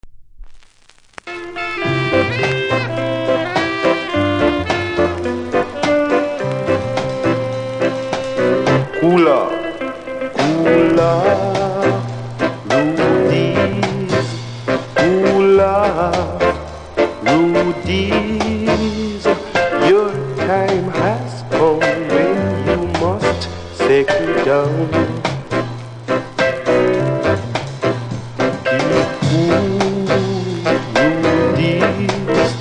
多少うすキズありますが音は良好なので試聴で確認下さい。
無録音部分に少しプレス起因のノイズ感じます。